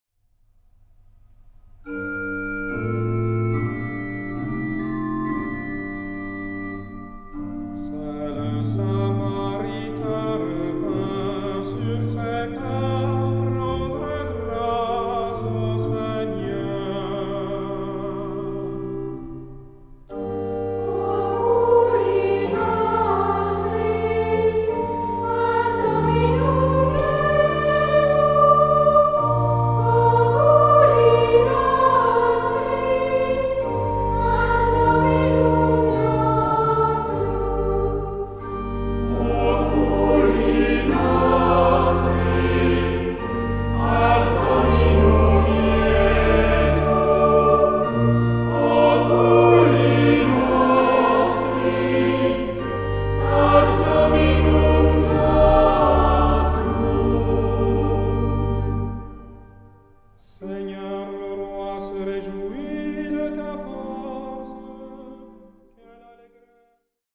Genre-Stil-Form: Tropar ; Psalmodie
Charakter des Stückes: andächtig
Chorgattung: SAH ODER SATB  (4 gemischter Chor Stimmen )
Instrumente: Orgel (1) ; Melodieinstrument (ad lib)
Tonart(en): g-moll